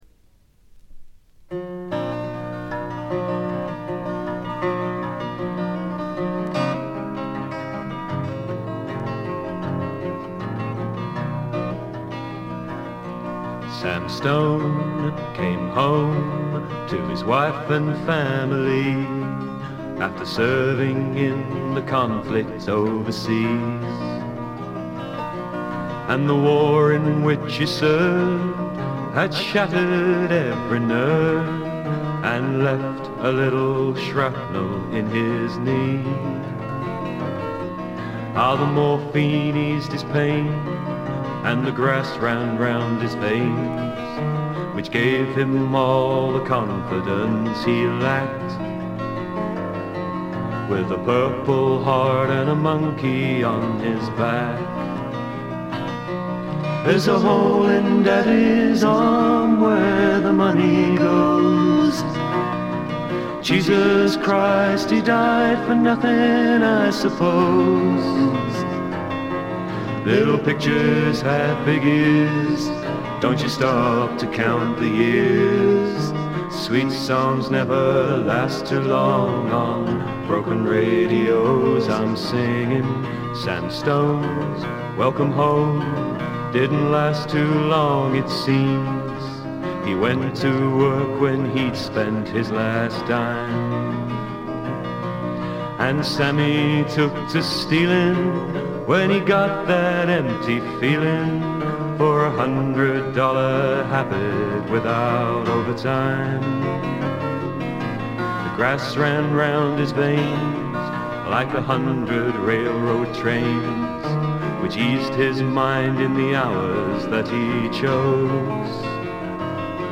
部分視聴ですが、ほとんどノイズ感無し。
全編を通じて飾り気のないシンプルな演奏で「木漏れ日フォーク」ならぬ「黄昏フォーク」といったおもむきですかね。
試聴曲は現品からの取り込み音源です。